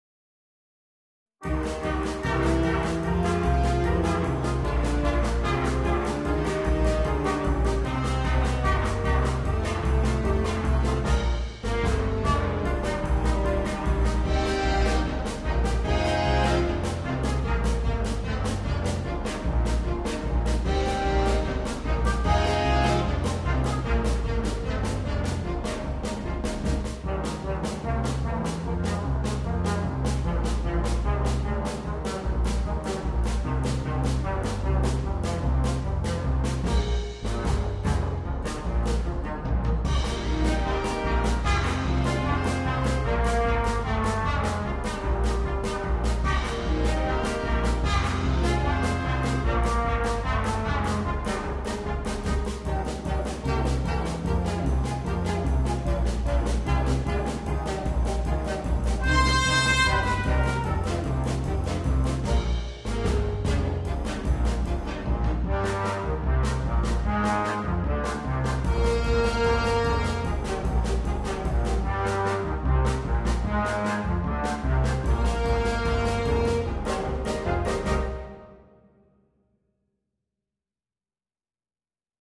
для биг-бэнда
• автор музыки: чешская народная песня